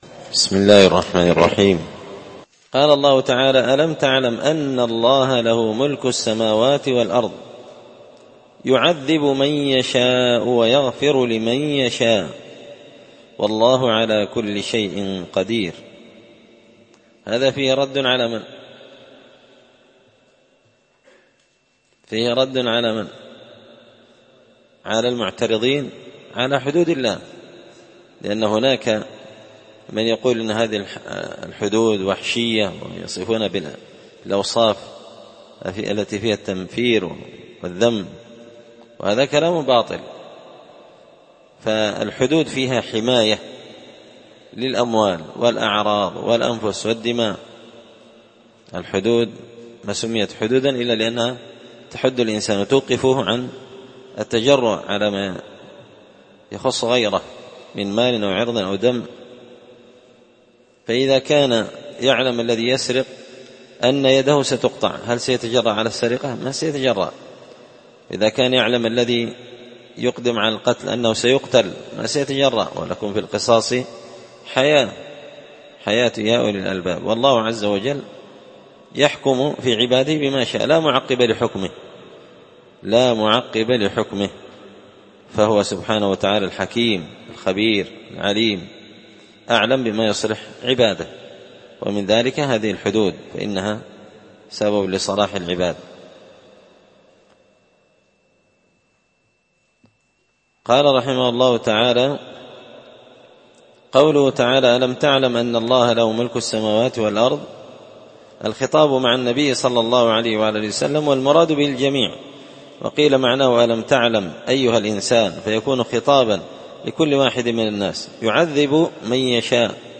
مسجد الفرقان